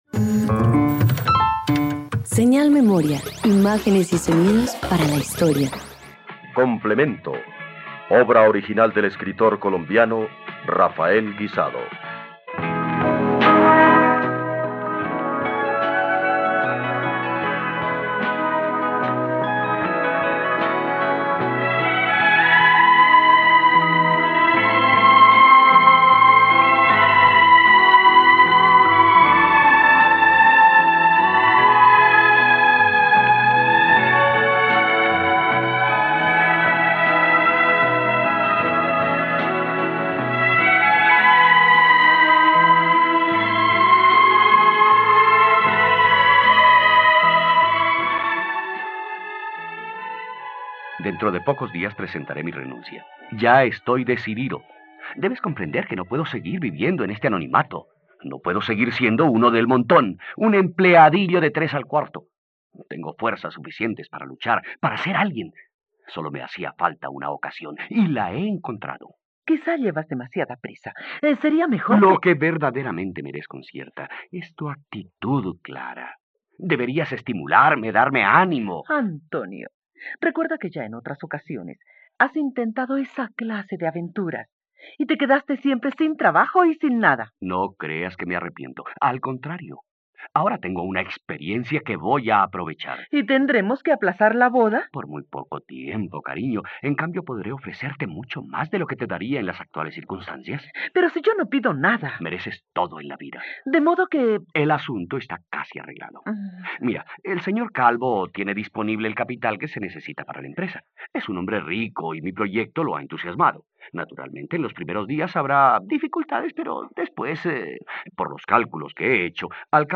..Radioteatro. Escucha la adaptación radiofónica de “Complementario” de Rafael Guizado por la plataforma streaming RTVCPlay.